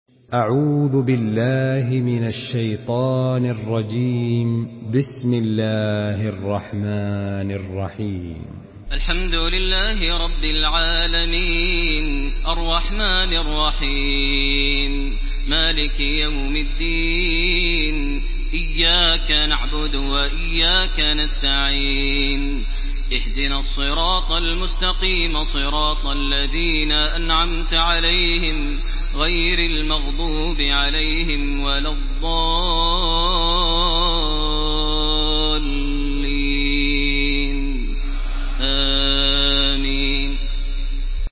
Surah Fatiha MP3 in the Voice of Makkah Taraweeh 1429 in Hafs Narration
Listen and download the full recitation in MP3 format via direct and fast links in multiple qualities to your mobile phone.
Murattal